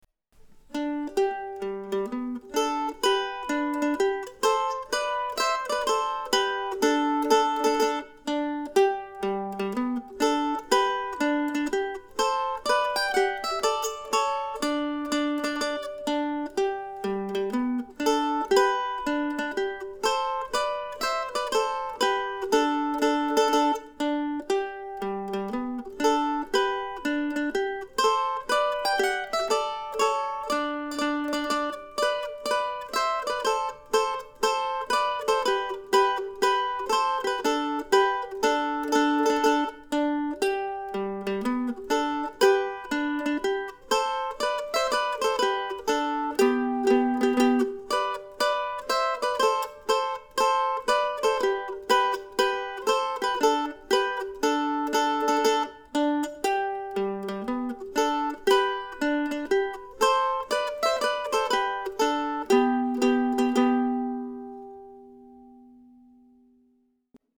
Here also is no. 5 from the book of William Bates duettinos.